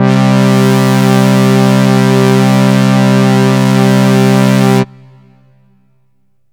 SYNTH GENERAL-4 0001.wav